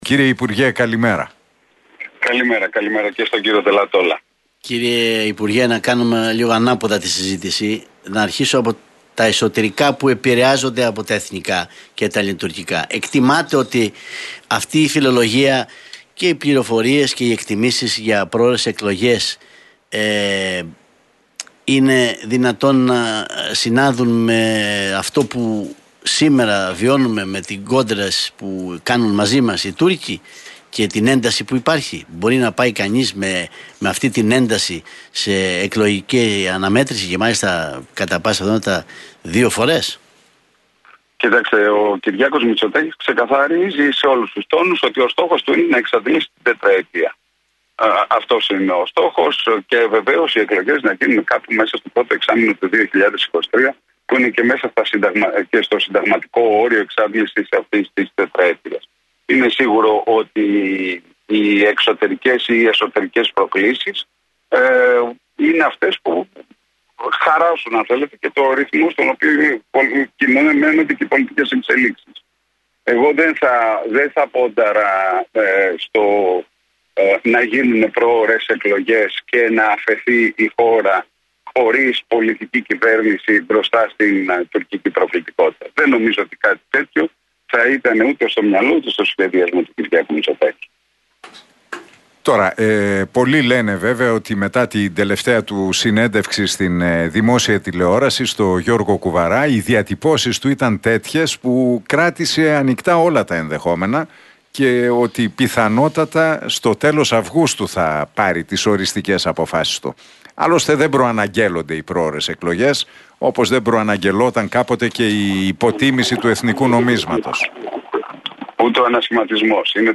παραχώρησε συνέντευξη στον Realfm 97,8